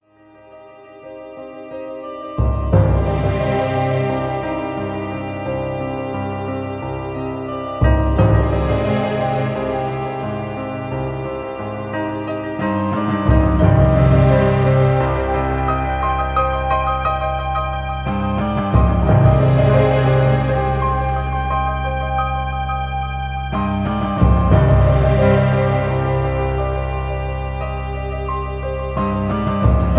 Piano,sax.